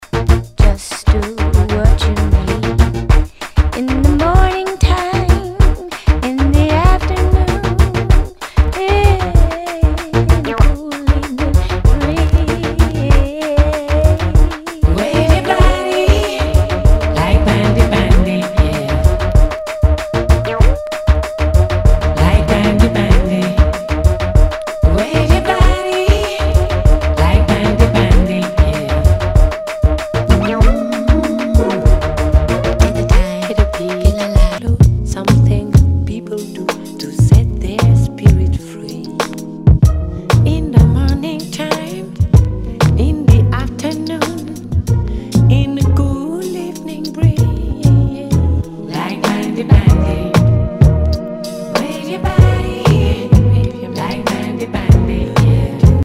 HIPHOP/R&B
ナイス！ダウンテンポ / R&B！